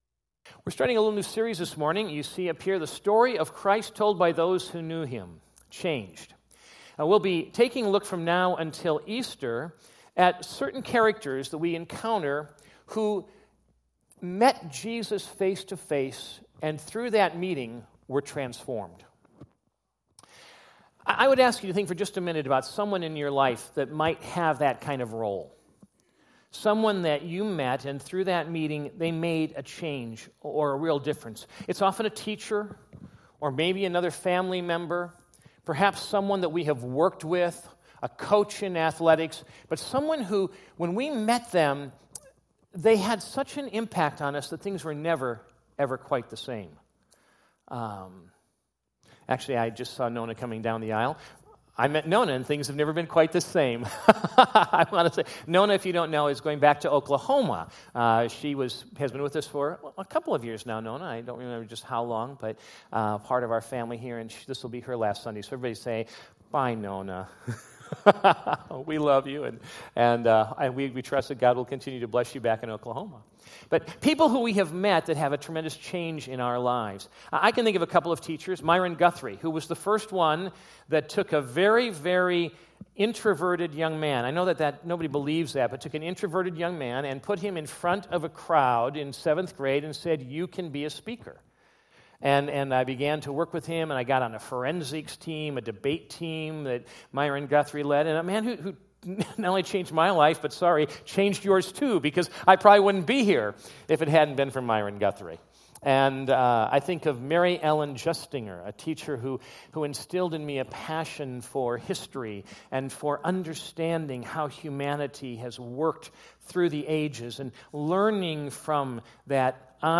2017 Categories Sunday Morning Message Download Audio John 1:35-51 Previous Back Next